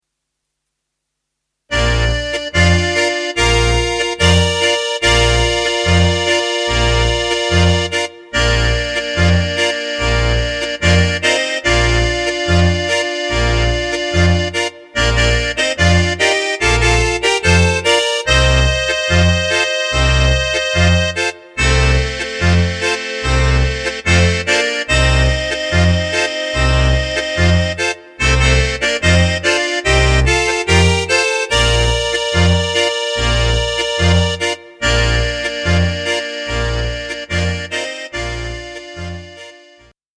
Bb-Dur